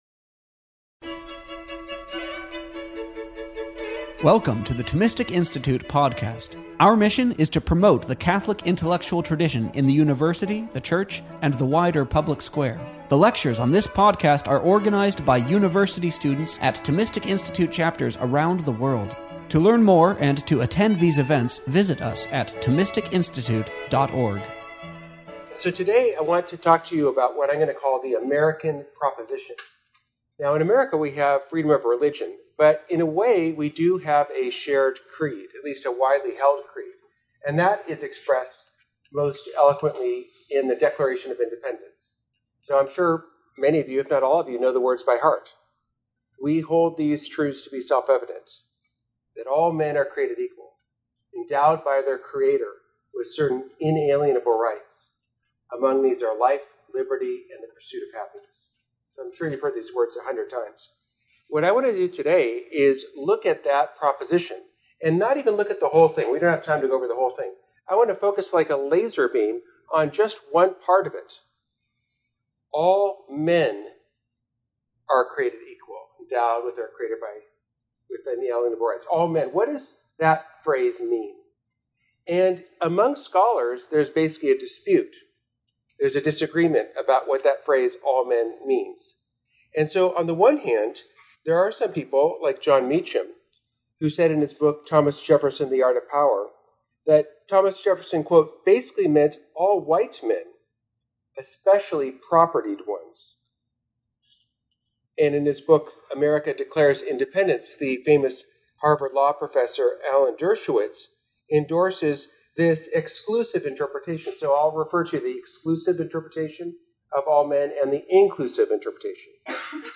This lecture was given on March 27th, 2025, at University of South Carolina.